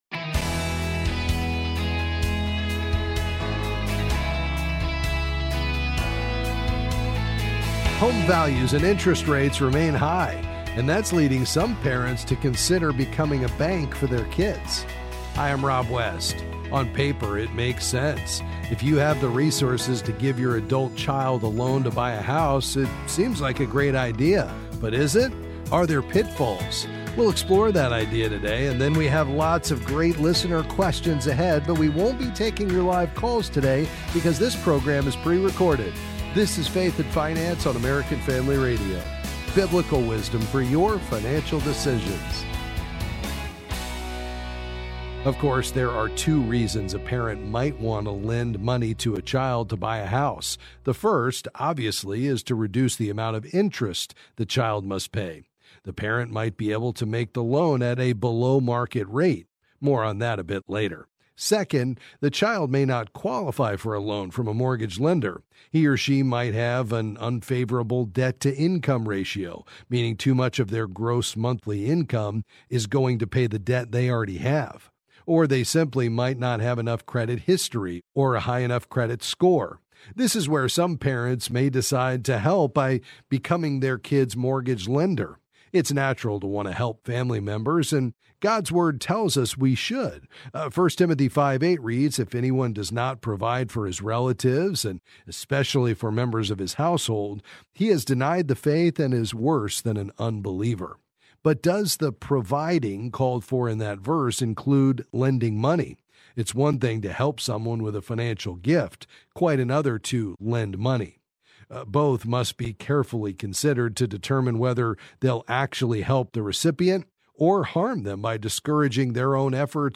Then he answers some calls and financial questions.